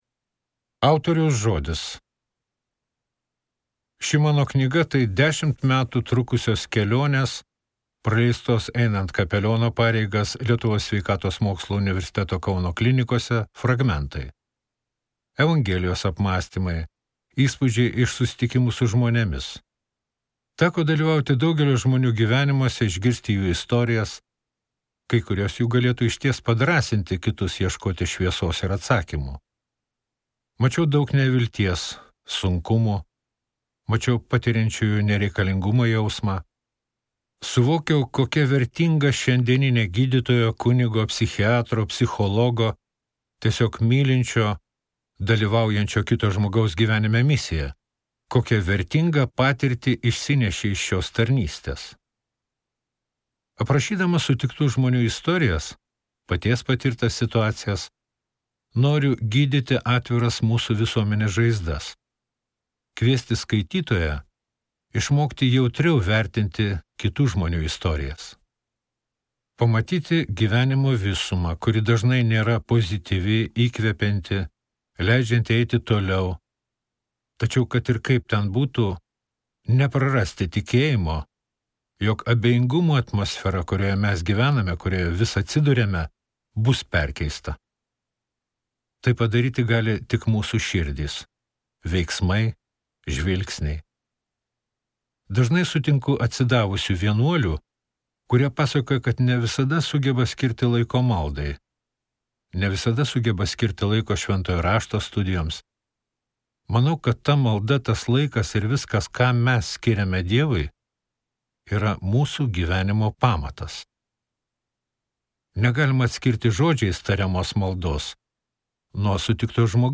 Kunigo širdies dienoraštis | Audioknygos | baltos lankos